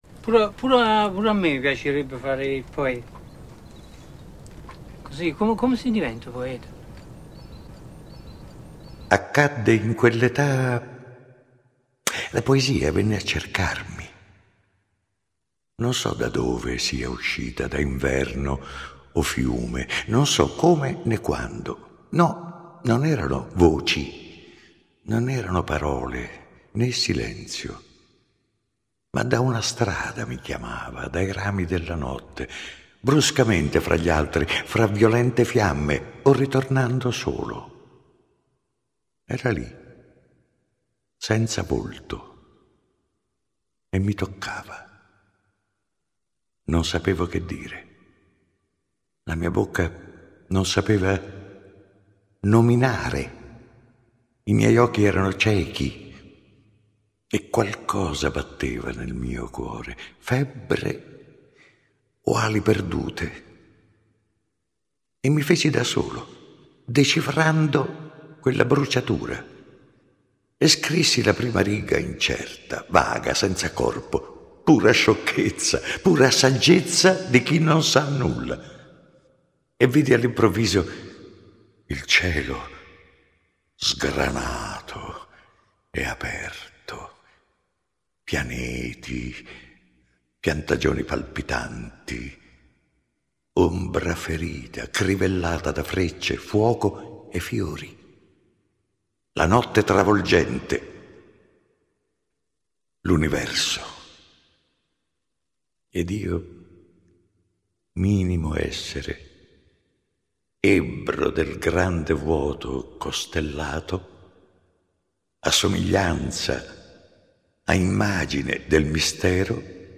(legge Arnoldo Foà)